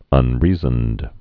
(ŭn-rēzənd)